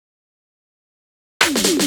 Fill 128 BPM (27).wav